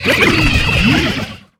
Cri de Prédastérie dans Pokémon Soleil et Lune.